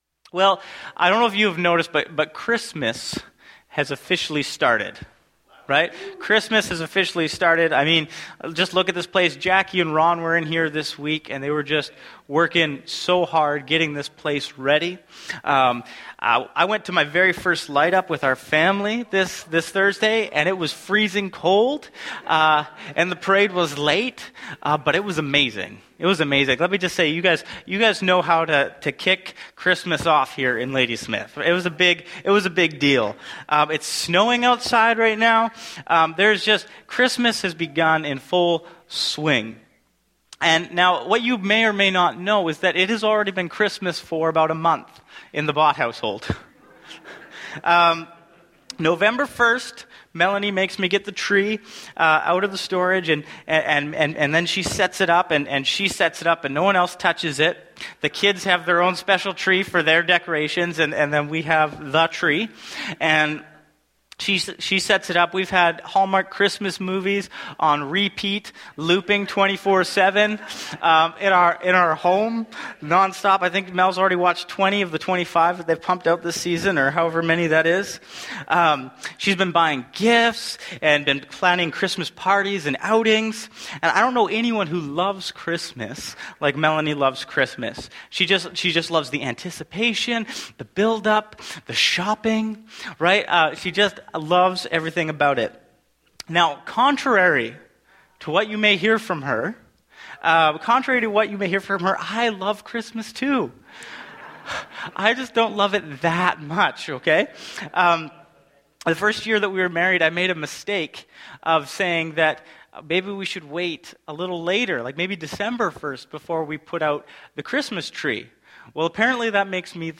Sermons | Bethel Church Ladysmith